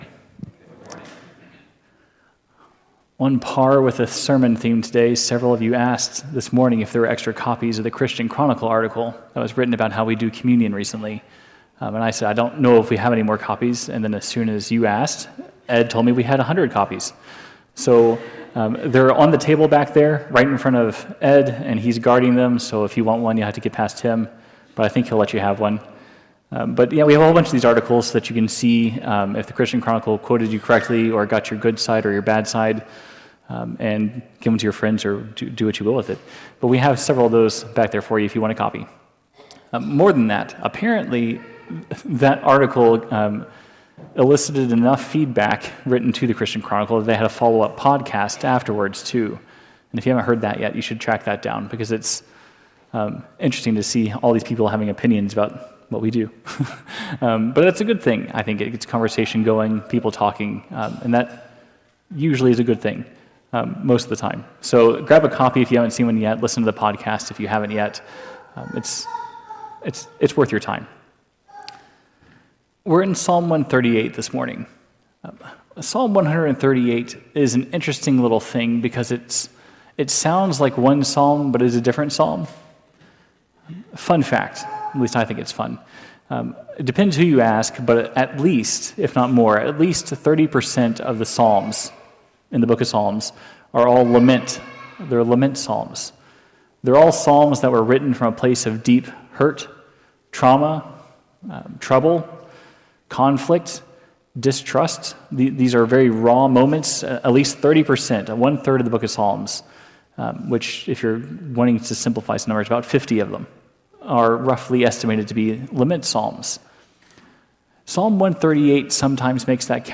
The sermon encourages persistent faith and deepening dependence on God’s grace.